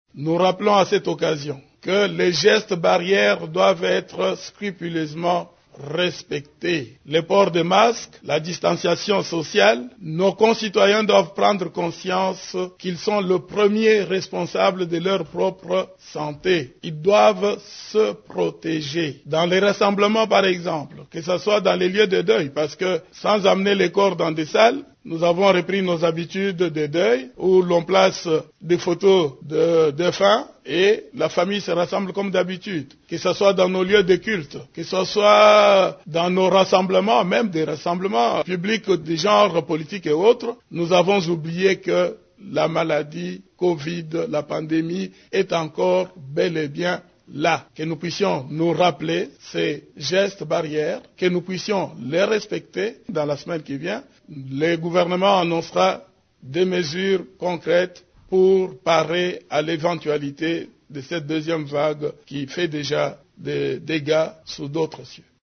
Le vice-premier ministre en charge de l’Intérieur, Gilbert Kankonde, s’est ainsi exprimé à l’issue de cette réunion :